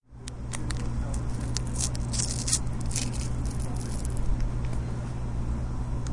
撕开糖包的声音，在斯坦福大学的咖啡馆CoHo录制。 用MAudio Microtrack 24/96在糖包旁边录音。